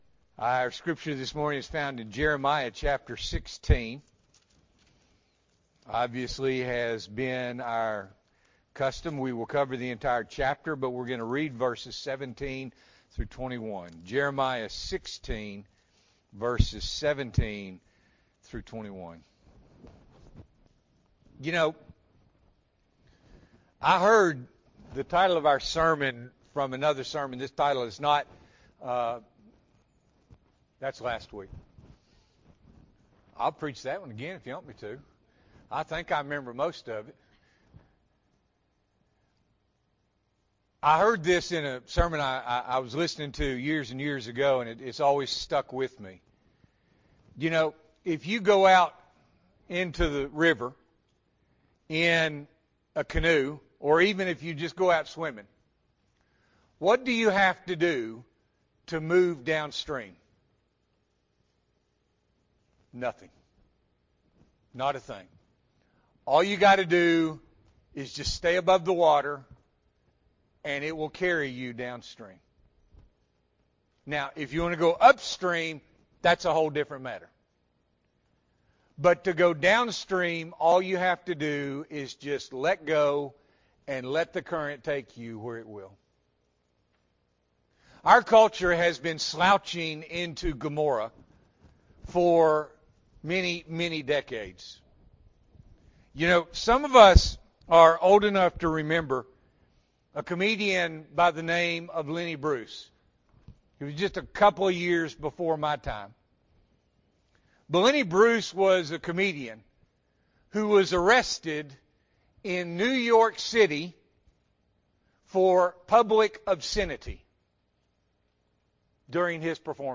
October 13, 2024 – Morning Worship